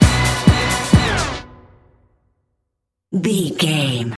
Fast paced
Ionian/Major
Fast
synthesiser
drum machine